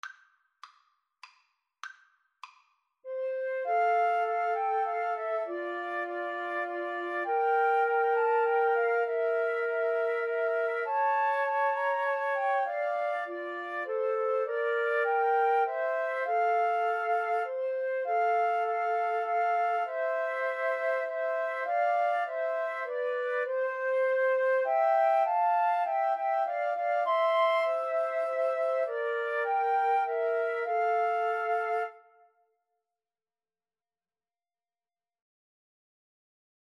Moderato
3/4 (View more 3/4 Music)